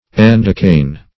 Endecane \En"de*cane\, n. [Gr.